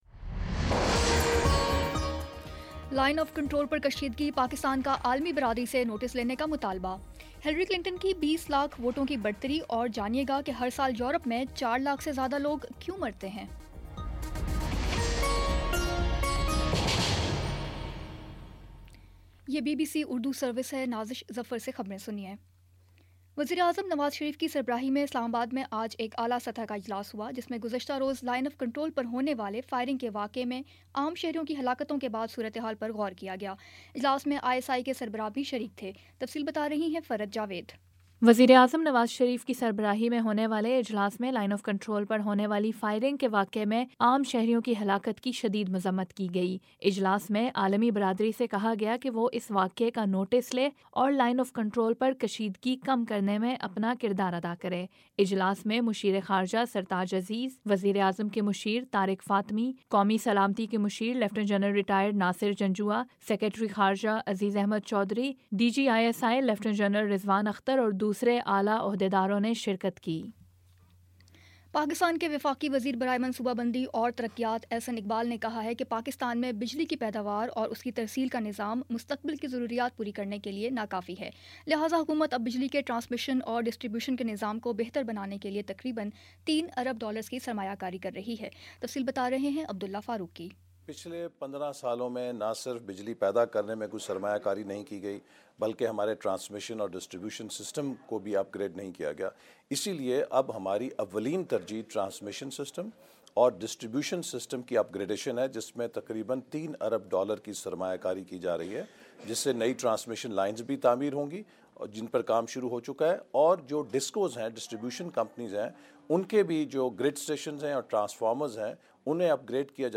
نومبر 24 : شام پانچ بجے کا نیوز بُلیٹن